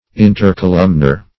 Search Result for " intercolumnar" : The Collaborative International Dictionary of English v.0.48: Intercolumnar \In`ter*co*lum"nar\, a. Between columns or pillars; as, the intercolumnar fibers of Poupart's ligament; an intercolumnar statue.